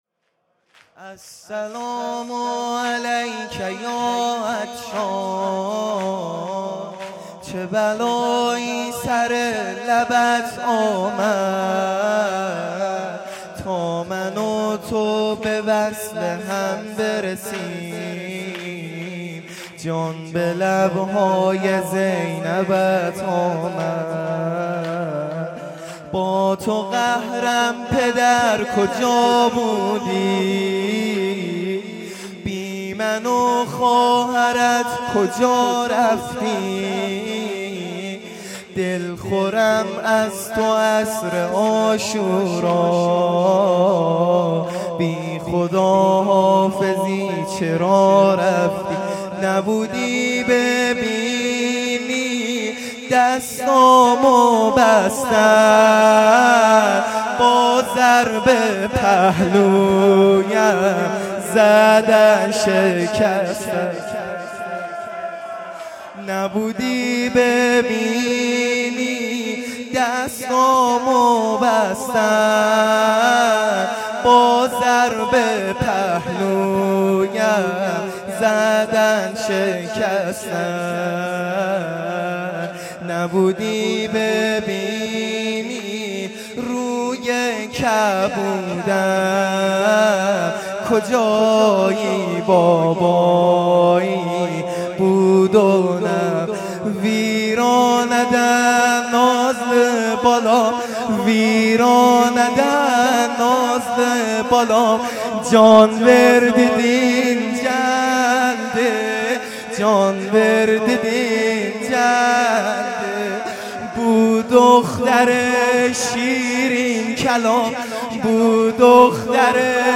شب 23 رمضان المبارک